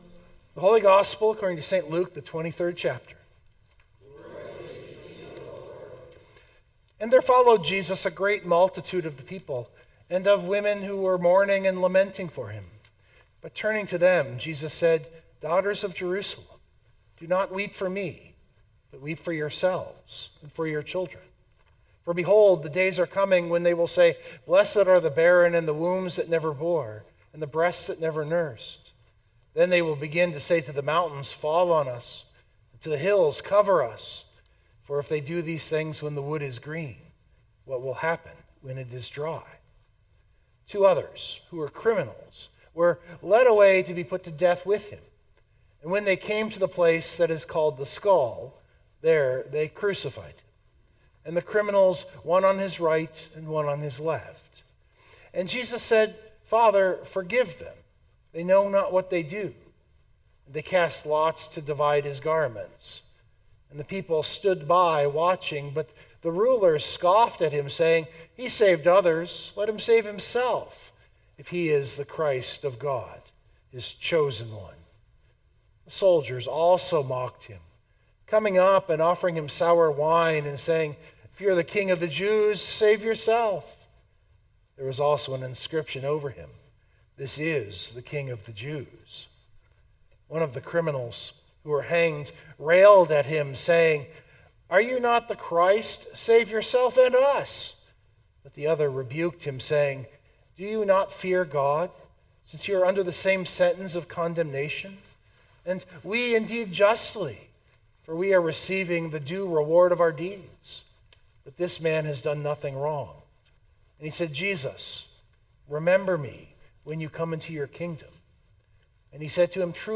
This was the Last Sunday of the Church Year, often called Christ the King Sunday.